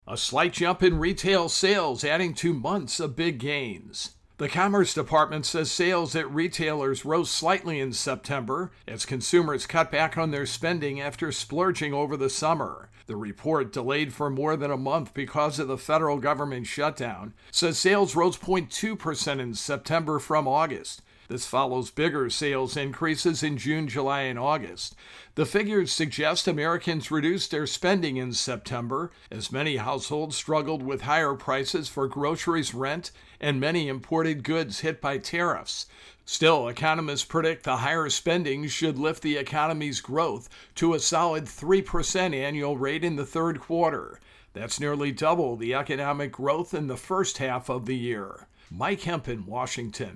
A slight jump in retail sales, adding to months of big gains. AP correspondent